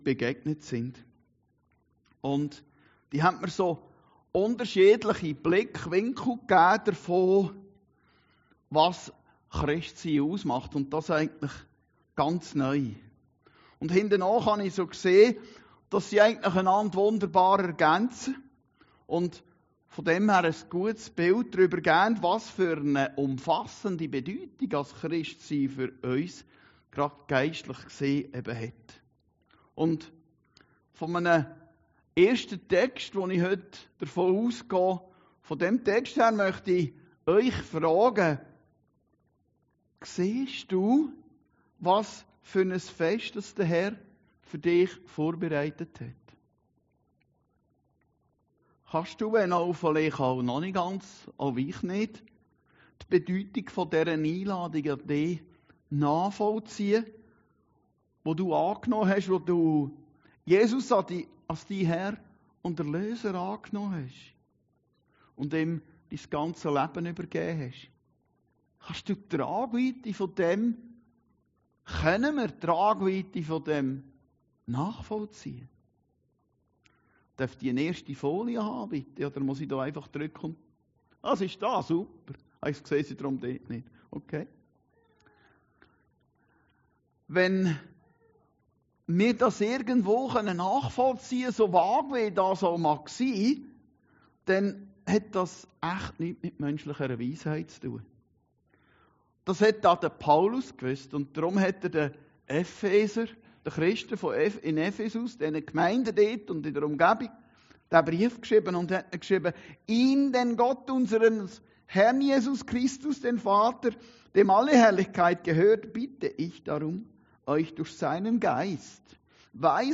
Predigten Heilsarmee Aargau Süd – AUGENÖFFNER GESCHICHTEN 3